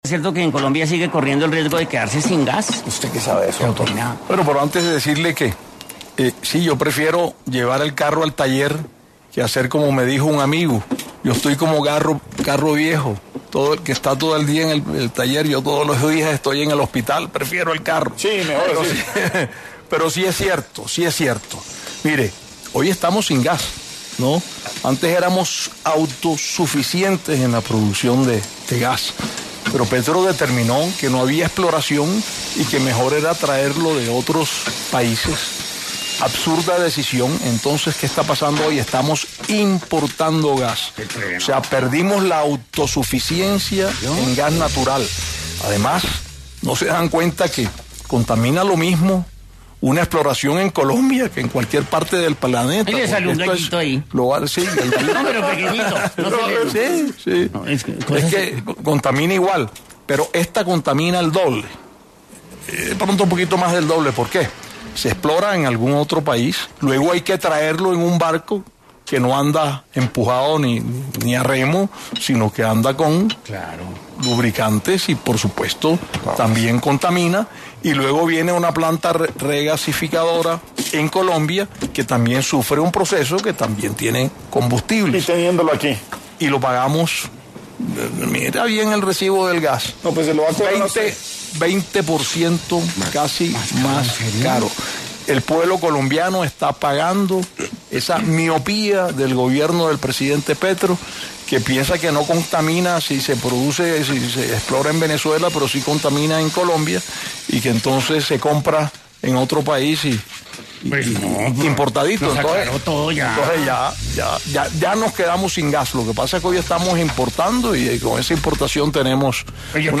En su paso por Sin Anestesia, un programa de Caracol Radio, estuvo Efraín Cepeda, quien habló sobre la exploración en Colombia y la autonomía que se perdió